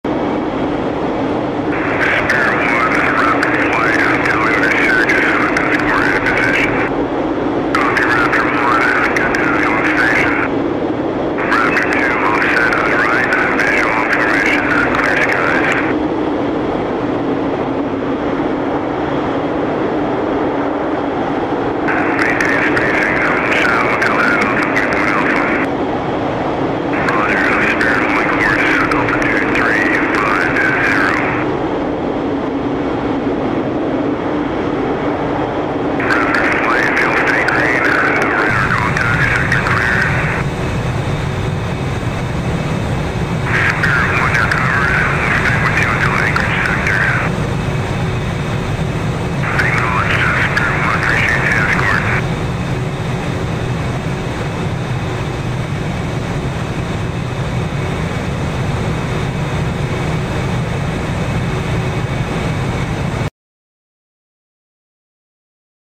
A B 2 Spirit flies over sound effects free download
A B-2 Spirit flies over Alaska under protection from six F-22 Raptors.